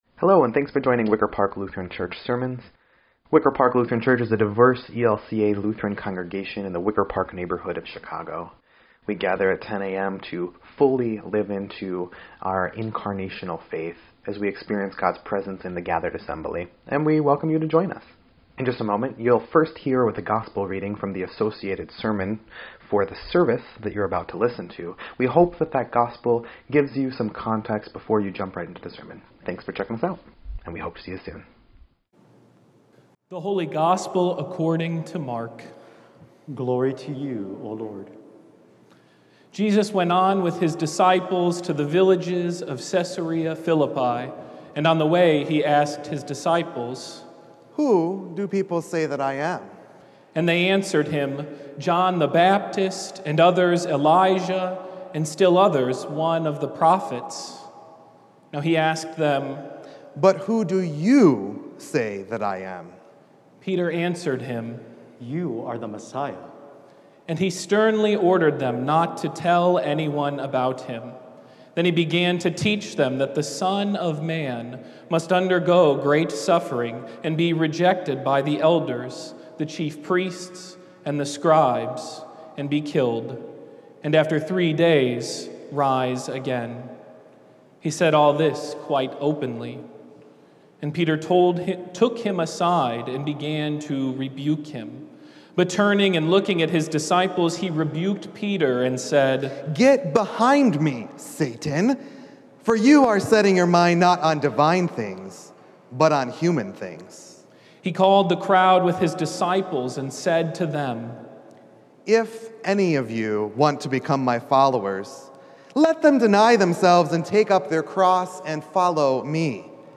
9.12.21-Sermon_EDIT.mp3